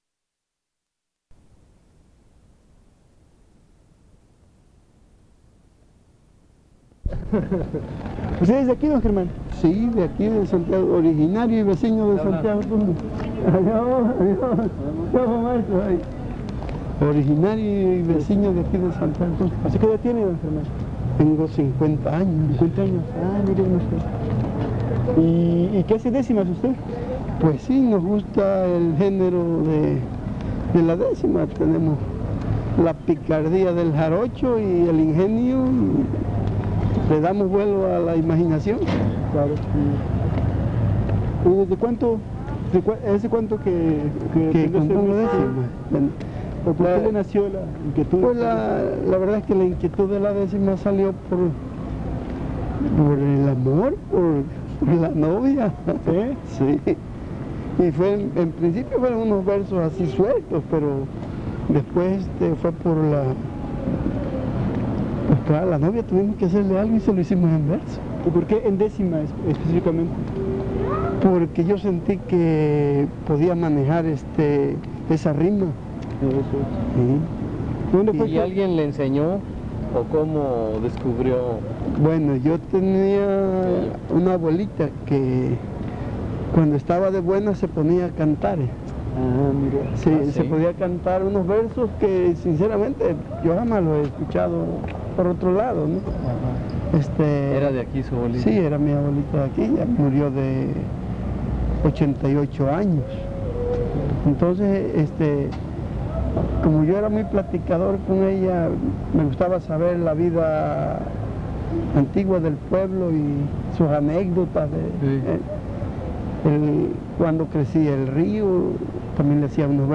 San Andrés Tuxtla, Veracruz
Entrevista